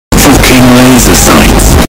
[ Earrape ] FOOKIN LASER SIGHTS.mp3 (45 KB)